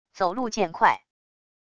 走路渐快wav音频